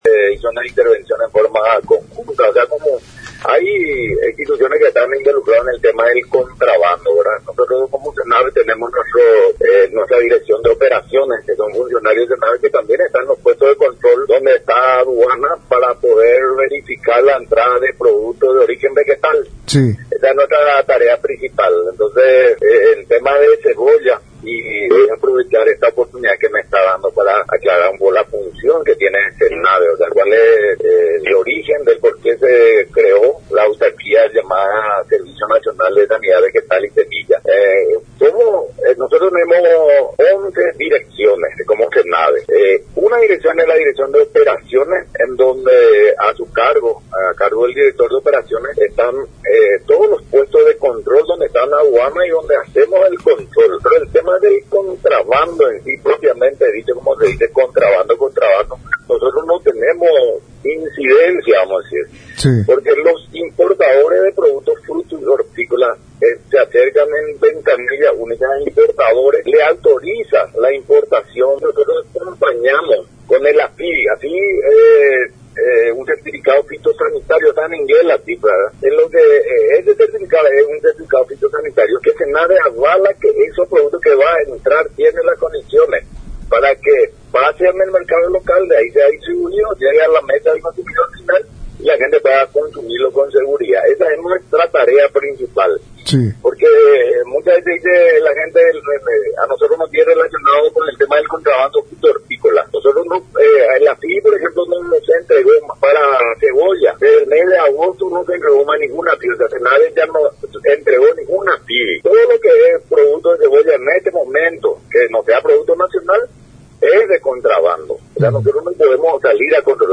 El presidente del SENAVE, Ing. Pastor Soria, en contacto Radio Nacional, destacó el firme compromiso del Gobierno Nacional, liderado por el Presidente de la República, para luchar frontalmente contra el comercio ilegal y del ingreso de mercaderías, que tanto daño ocasiona al país.